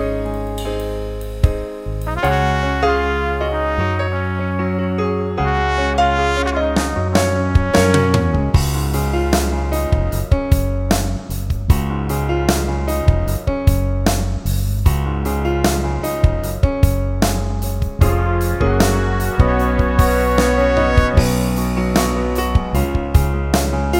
no BV Soundtracks 2:20 Buy £1.50